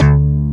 SLAP 1.wav